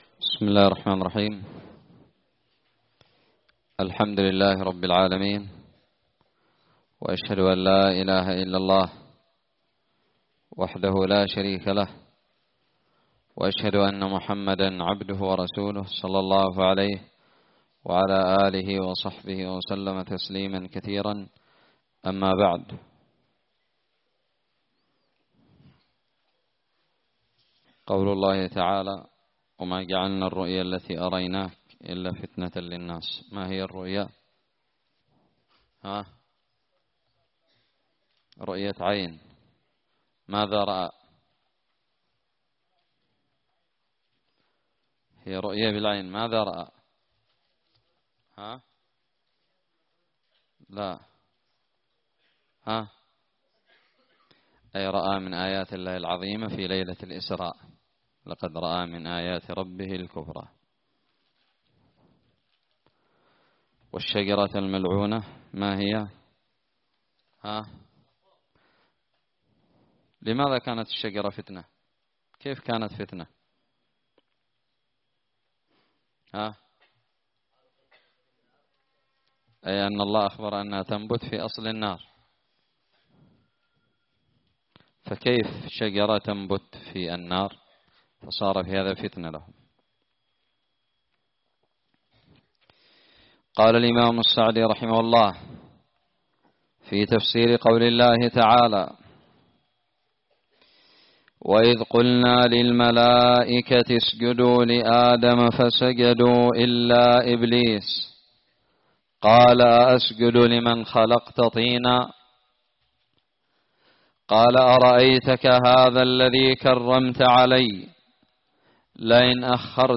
الدرس السابع عشر من تفسير سورة الإسراء
ألقيت بدار الحديث السلفية للعلوم الشرعية بالضالع